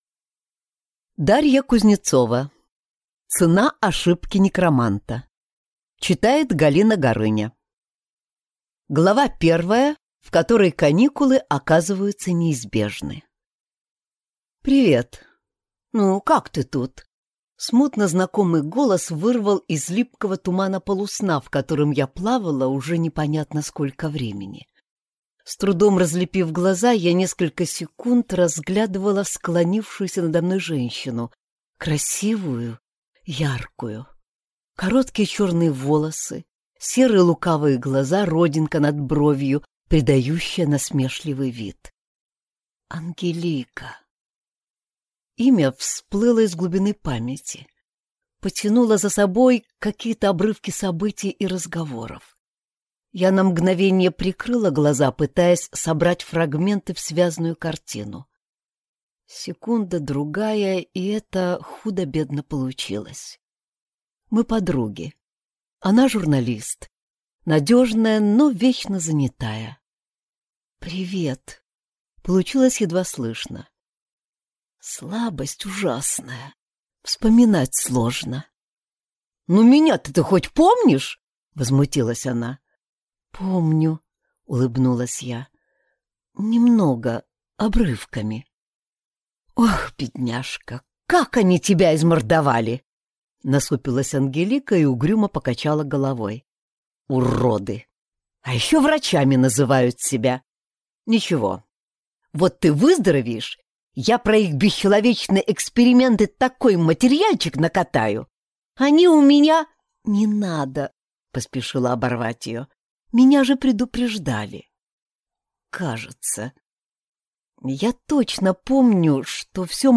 Аудиокнига Цена ошибки некроманта | Библиотека аудиокниг